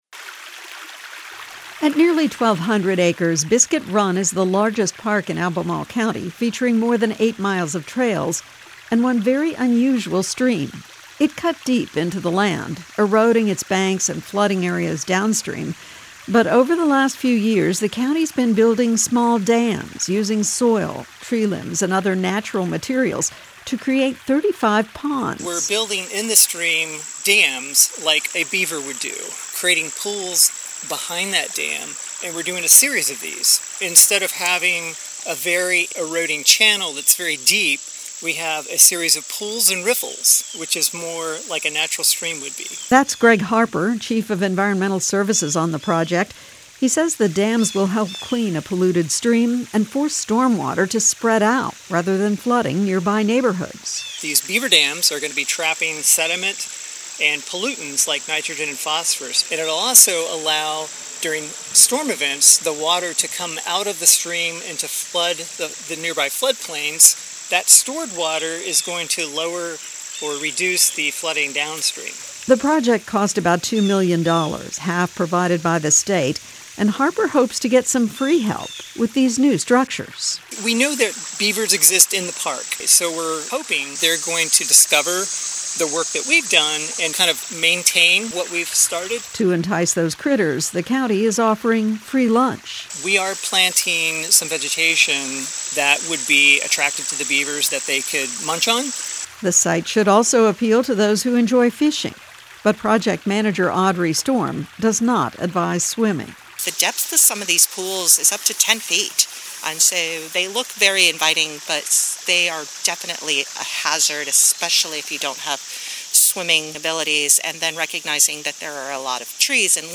I love this news report with a fiery passion.